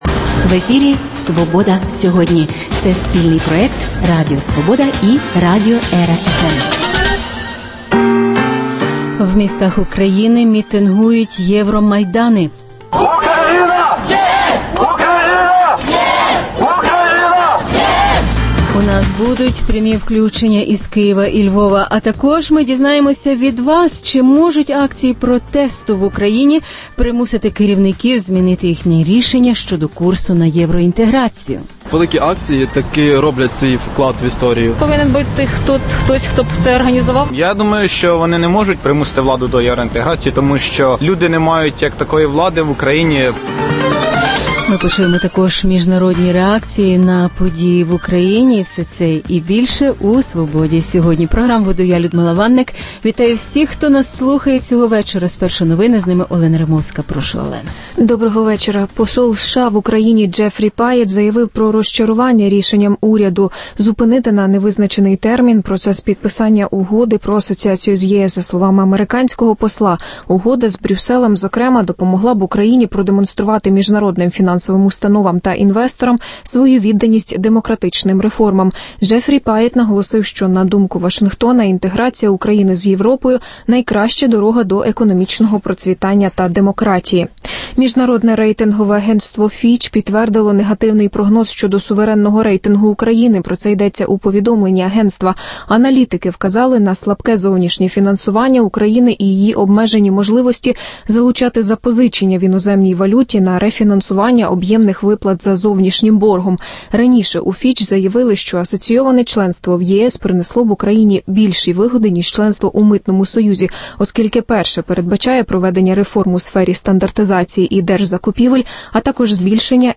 В Українських містах мітингують євромайдани. Прямі включення із Києва і Львова Чи можуть акції протесту в Україні примусити керівників змінити їхнє рішення щодо курсу на євроінтеграцію?